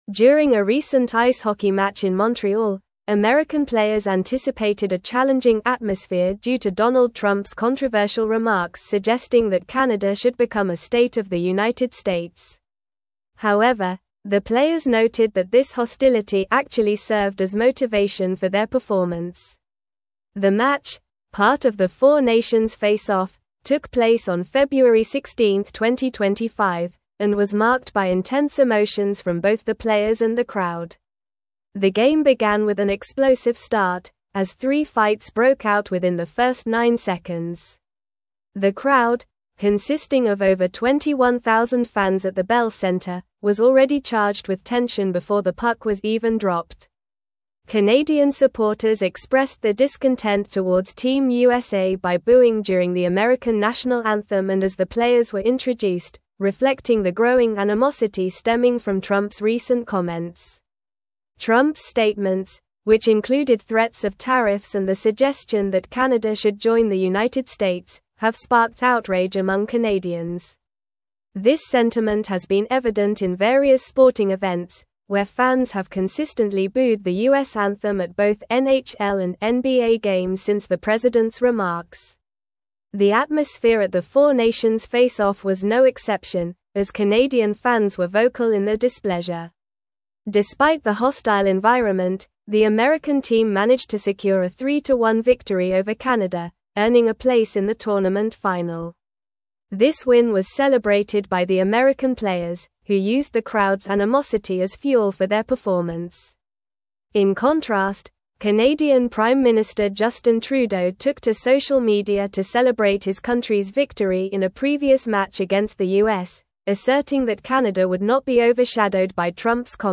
Normal
English-Newsroom-Advanced-NORMAL-Reading-Intense-Rivalry-Ignites-During-4-Nations-Face-Off.mp3